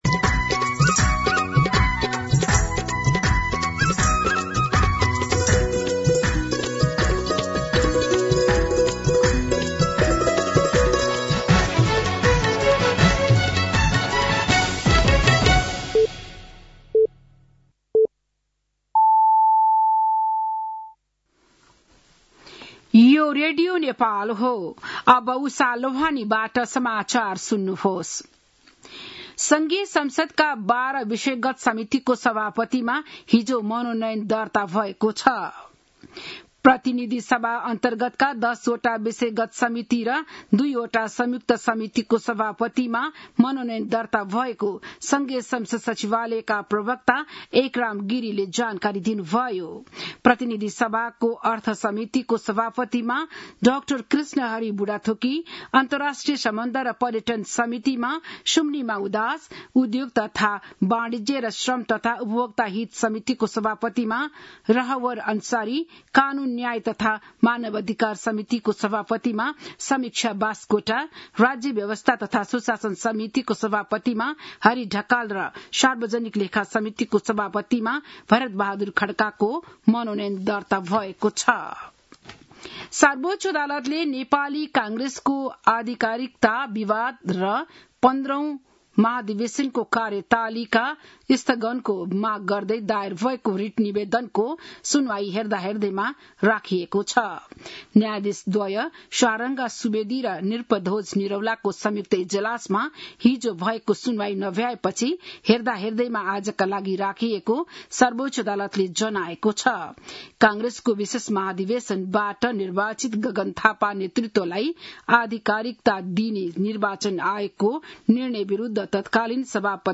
बिहान ११ बजेको नेपाली समाचार : ४ वैशाख , २०८३